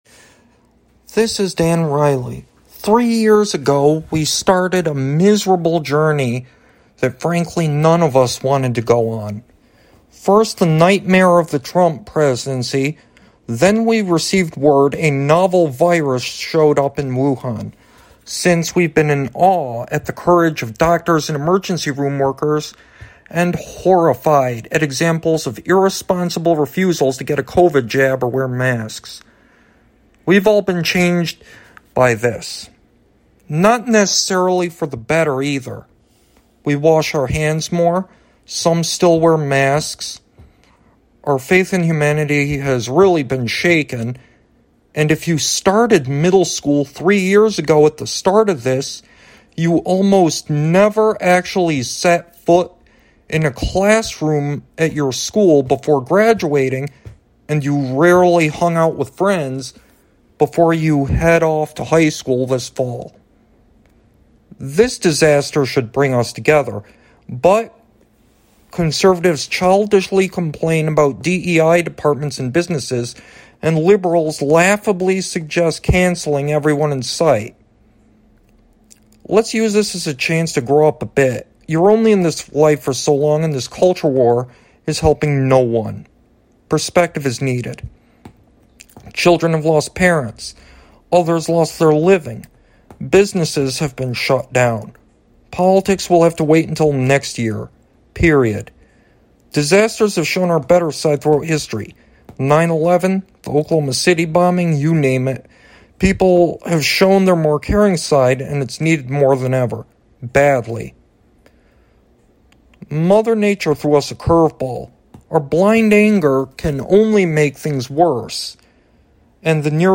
Audio Commentary